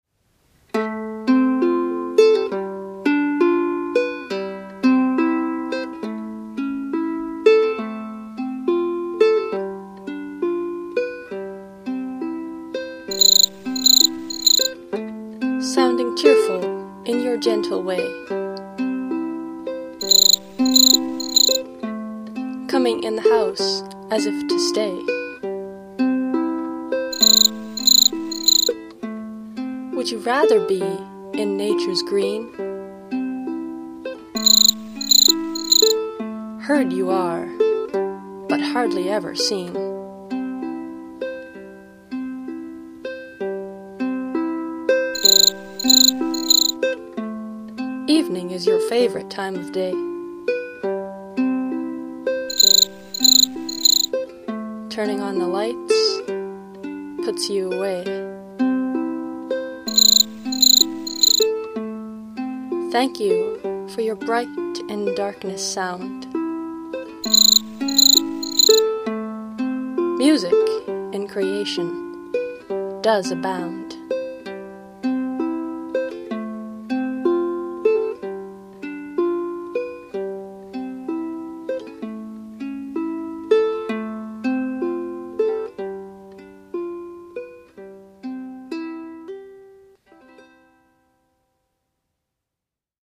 Instrument: Lady – tenor Flea ukulele